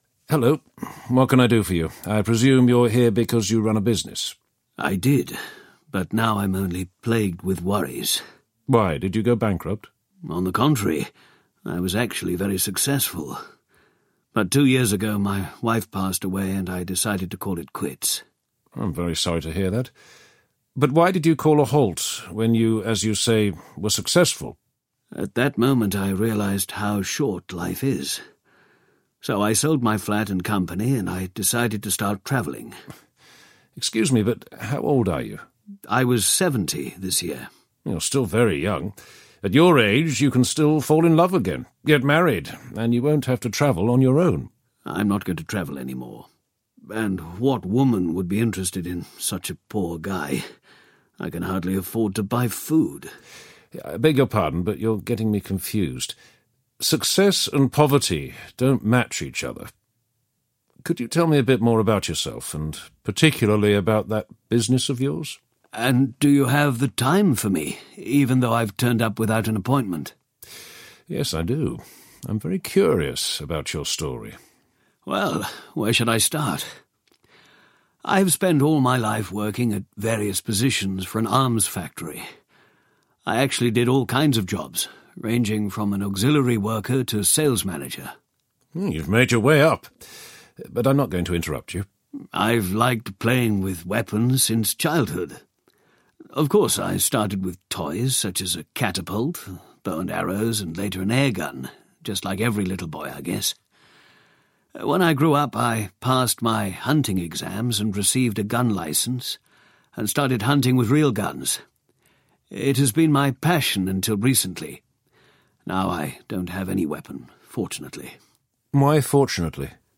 Business Risk Buster Intervenes 5 audiokniha
Ukázka z knihy